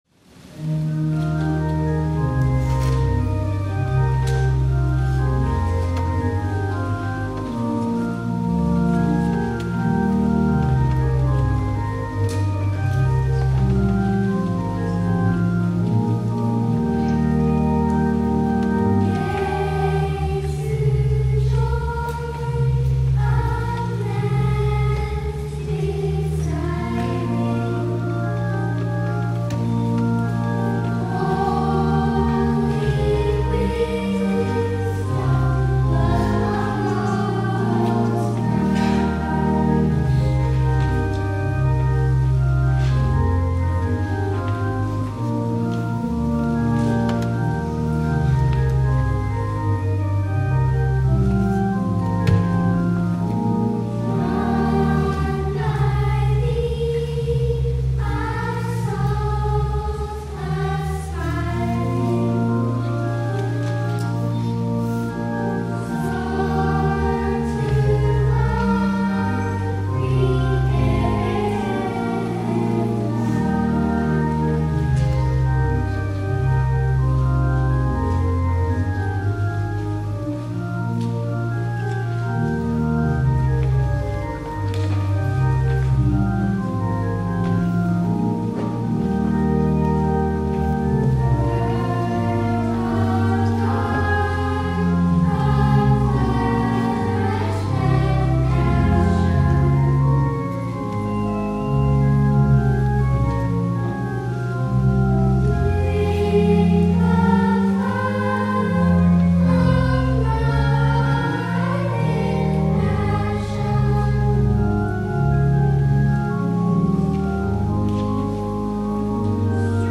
TRANSFIGURATION OF THE LORD - Youth Sunday
THE ANTHEM
Youth Ensemble and Chancel Choirs